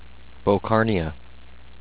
bow-CAR-knee-uh